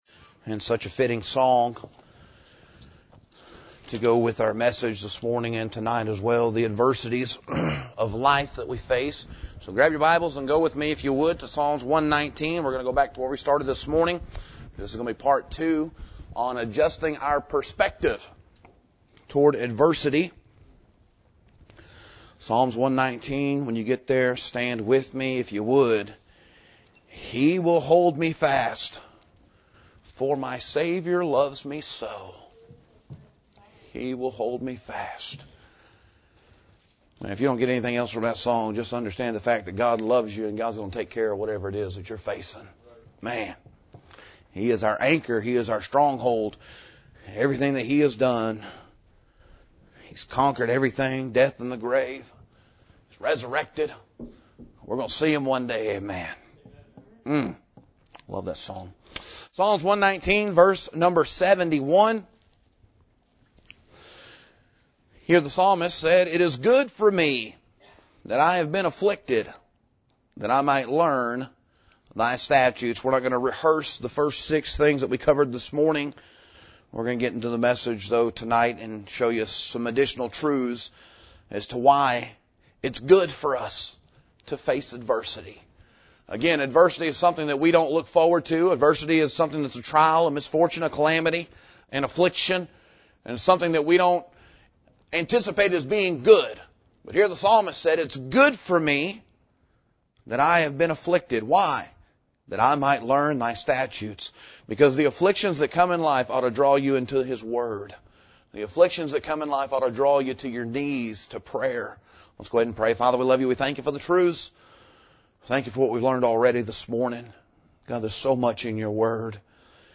Tonight’s message lays out additional biblical reasons why God allows adversity—and how each purpose can produce spiritua